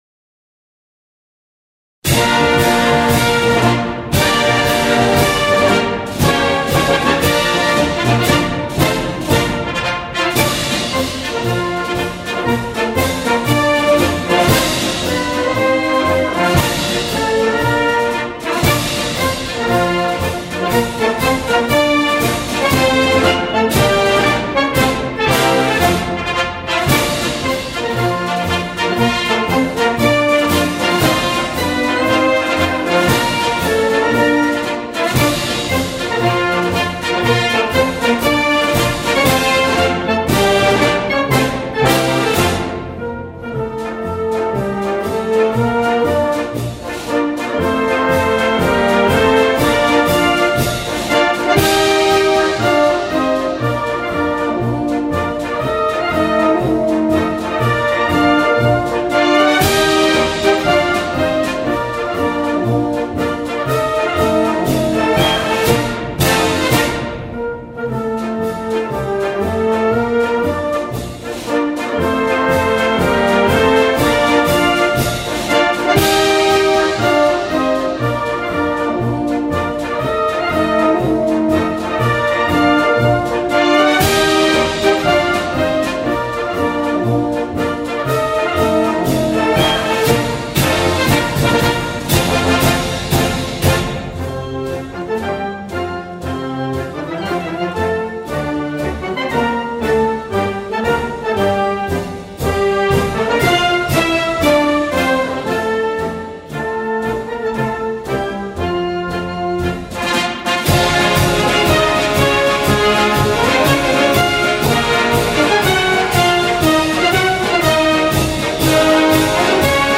Ihr Ruf – national und international – ist wohlbegründet, denn in idealer Weise erfüllt sie vielschichtige Aufgaben als Militärmusik und Konzertblasorchester von Rang: Feierlichen Anlässen der Republik Österreich, des Landes Kärnten und des Bundesheeres verleiht sie durch ihre gestaltende Mitwirkung Würde und Stil.
Hörproben der Militärmusik Kärnten
khevenhueller_marsch.mp3